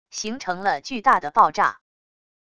形成了巨大的爆炸wav音频